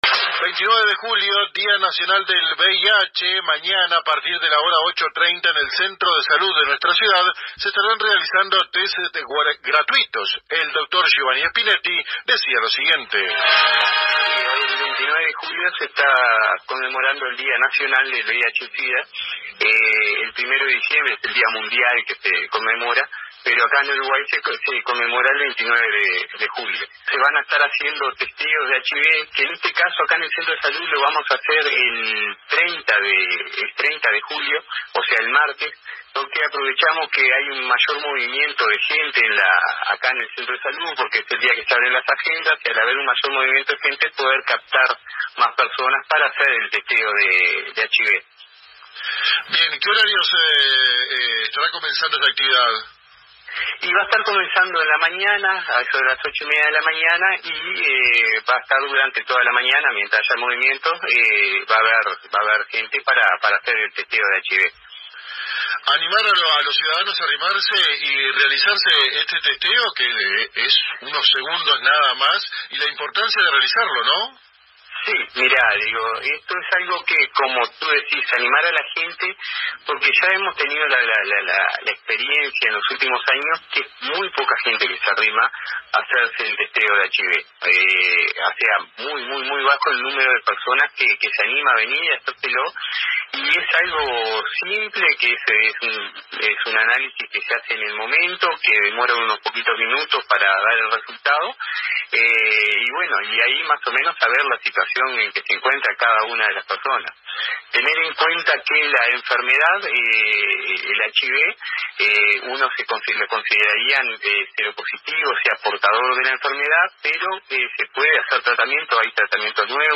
Fuente: AM 1110 - Paso de los Toros